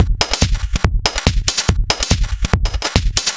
Index of /musicradar/uk-garage-samples/142bpm Lines n Loops/Beats